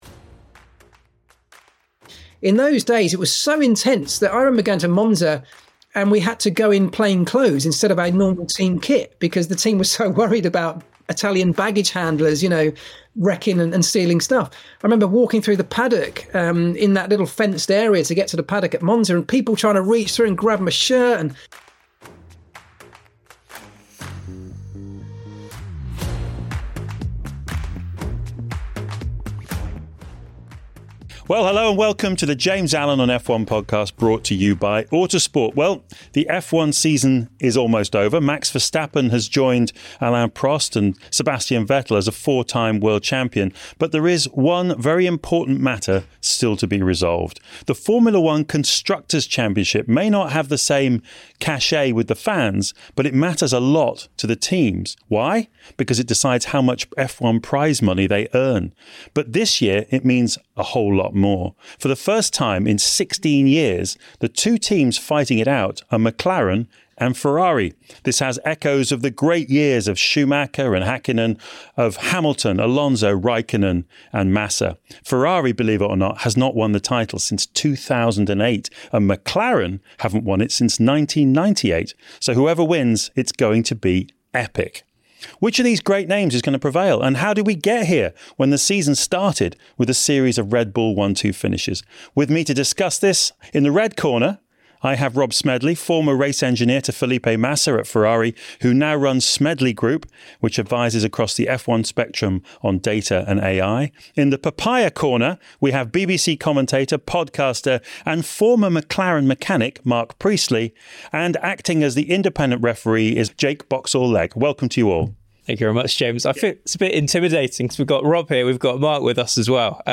Three-time BAFTA award-winning F1 commentator James Allen returns to the broadcast mic with a thoughtful and engaging new podcast, looking at the human side of the sport. Every episode will feature an insightful 20-minute interview with a prominent figure from inside and around the sport focusing on themes beyond the everyday news cycle. Joining James in the studio for analysis and discussion will be a rotating cast of key figures from Autosport and Motorsport’s global editorial team and guests